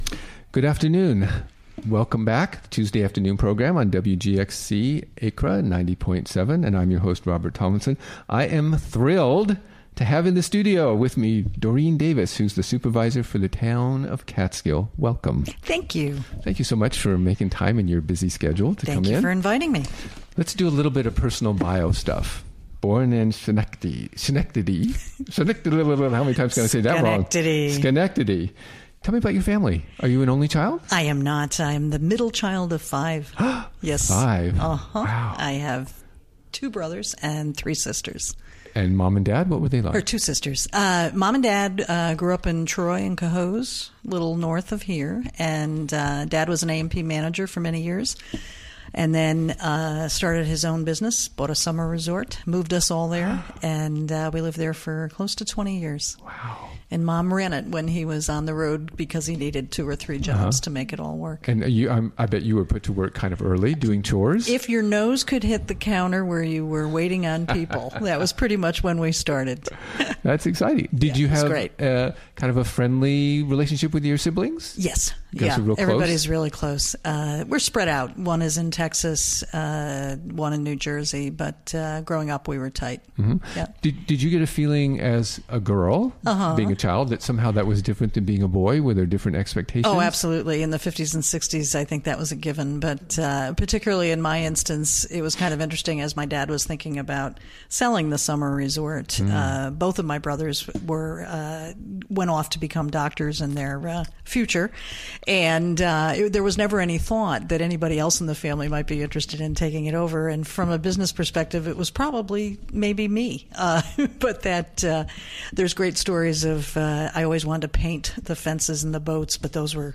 Recorded during the WGXC Afternoon Show Tuesday, April 11, 2017.